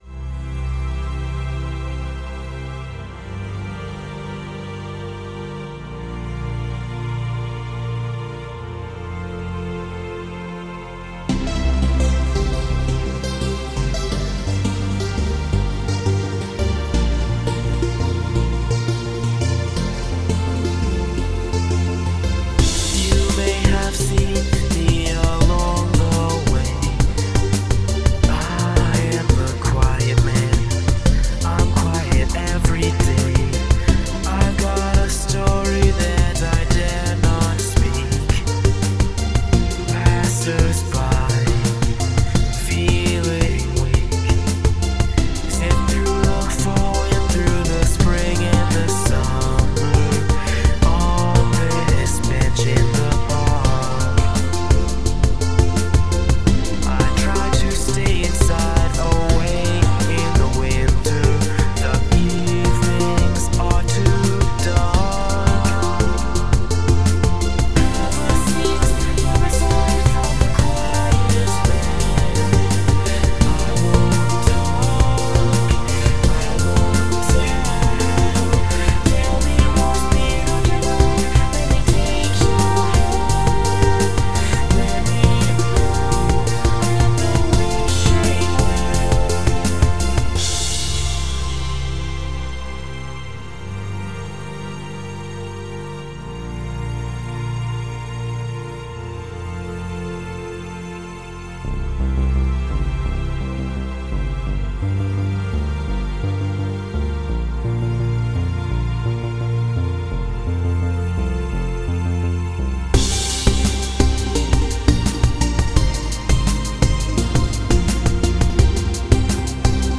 Music / Techno
remix techno electronic